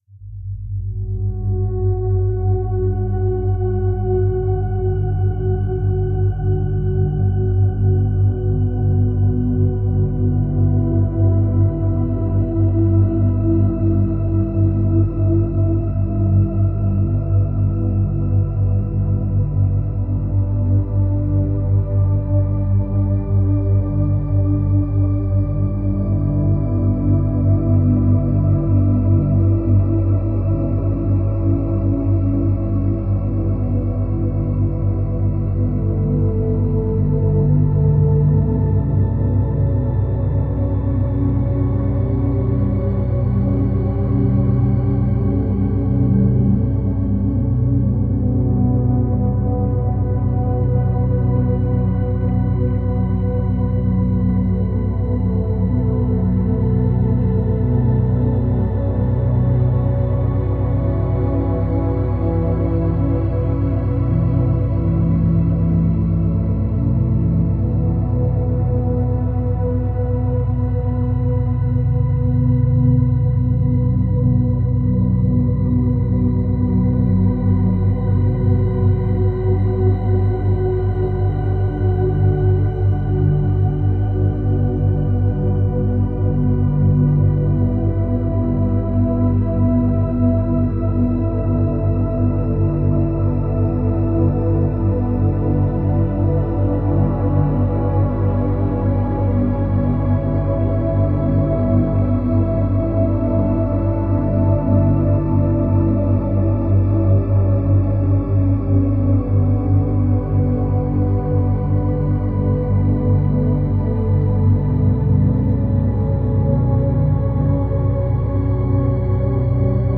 95Hz – 103Hz
Binaural Beats